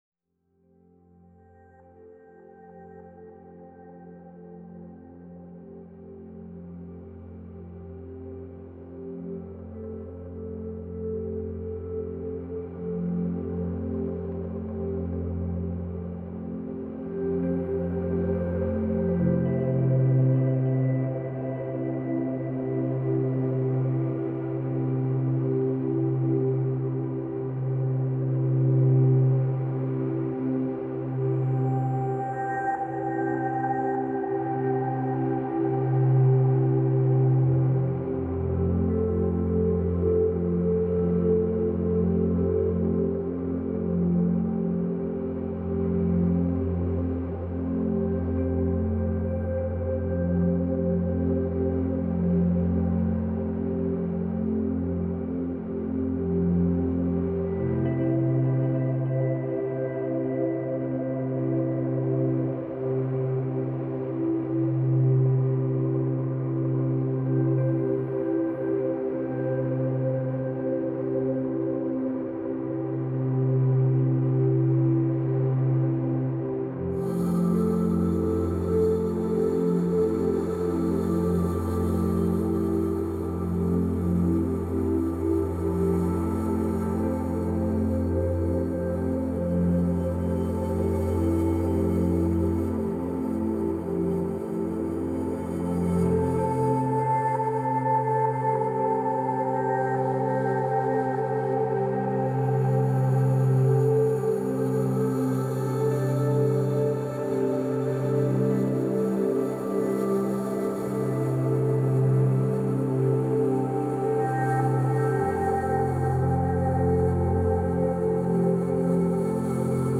آرامش بخش , امبینت , موسیقی بی کلام